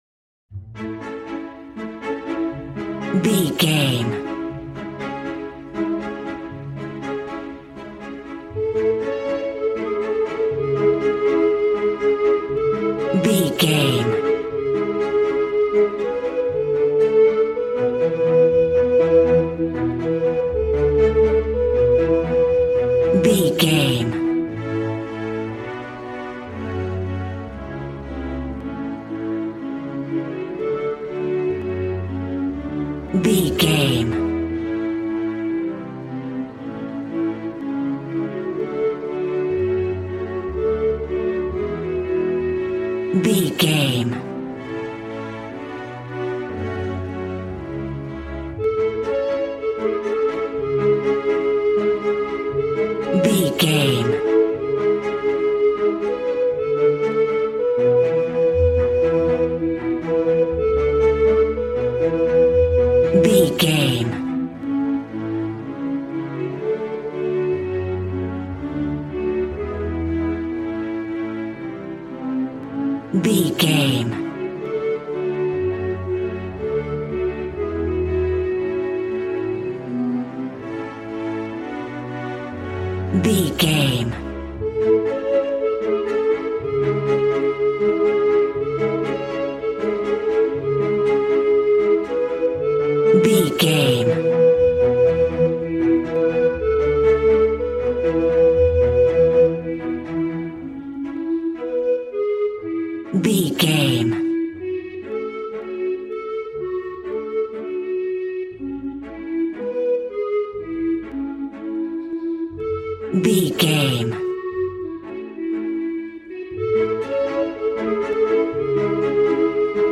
A warm and stunning piece of playful classical music.
Regal and romantic, a classy piece of classical music.
Ionian/Major
regal
piano
violin
strings